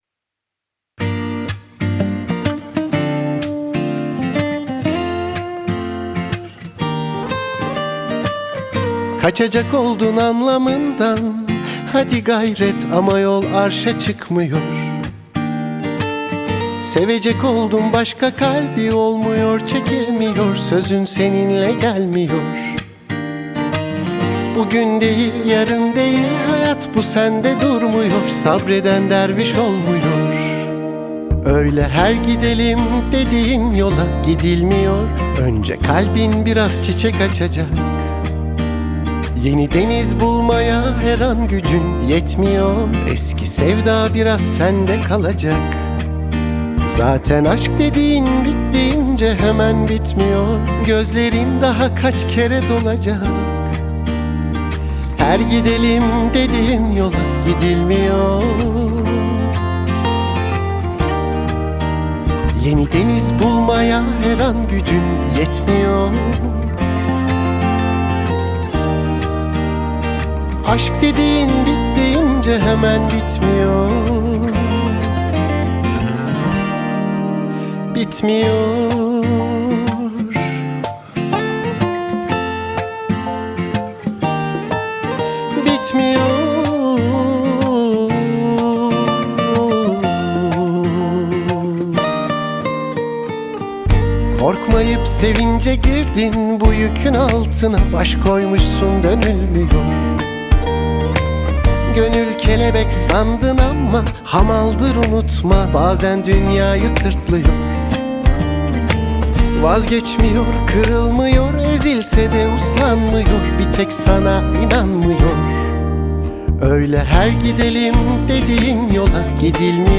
dizi müziği, duygusal huzurlu rahatlatıcı şarkı.